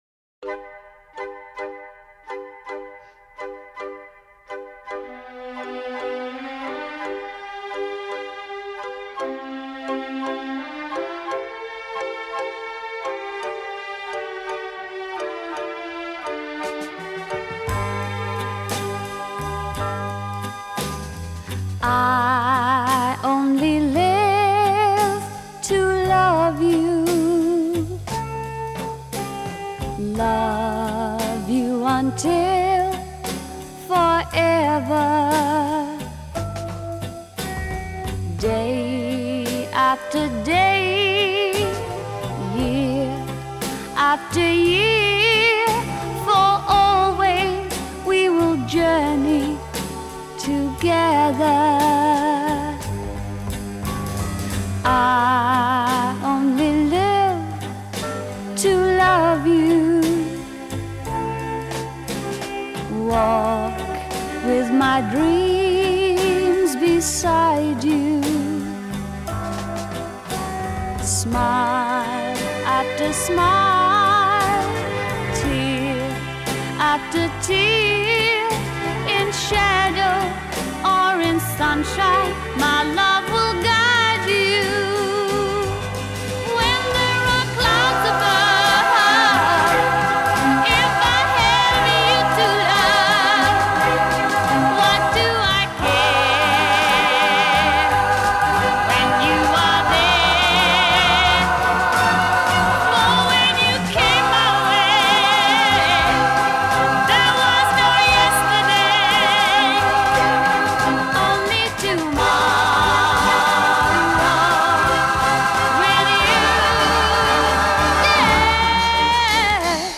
Vinyl, 7", 45 RPM, Single
Genre: Pop
Style: Vocal